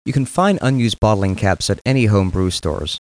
botlcaps.mp3